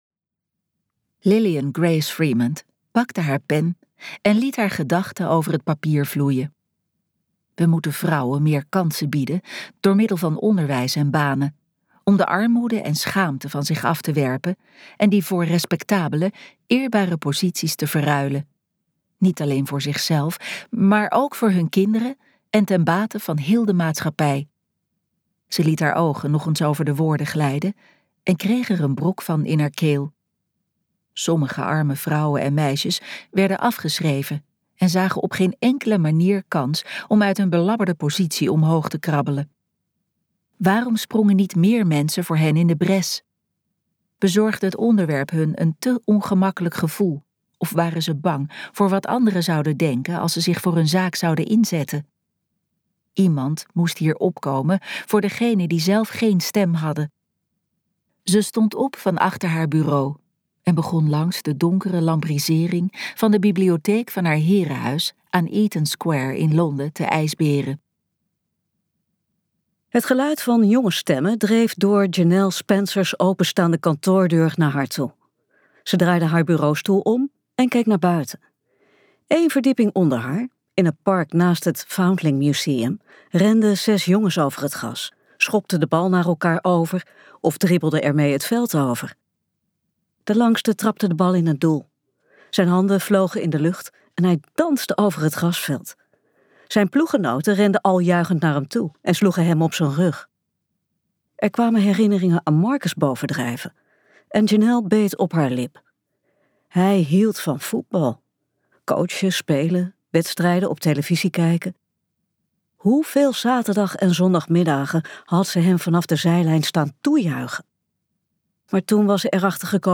KokBoekencentrum | Het vondelingenhuis van londen luisterboek